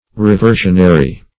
Reversionary \Re*ver"sion*a*ry\, a. (Law)